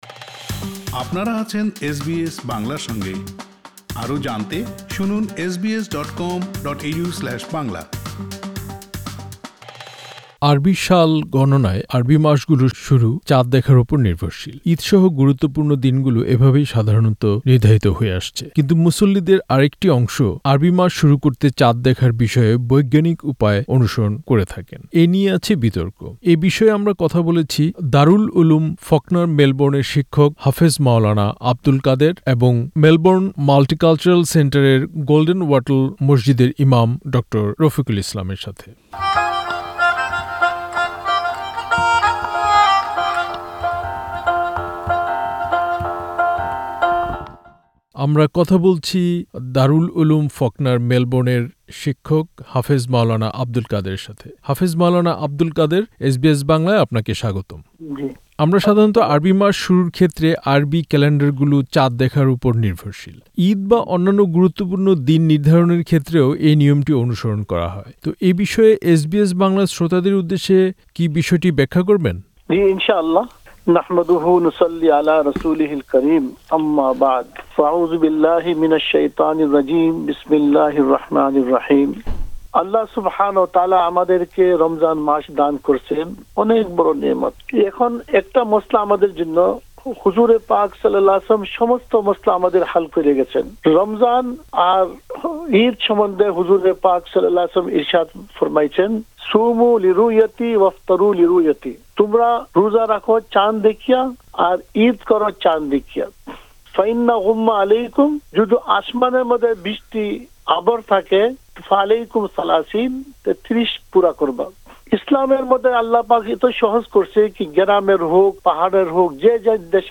পুরো সাক্ষাৎকার দু'টি শুনতে ওপরের অডিও প্লেয়ারটিতে ক্লিক করুন